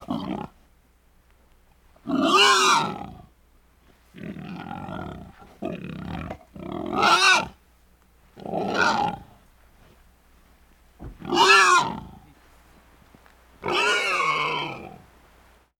Звуки кабанов
Кабан хрюкает и издает звуки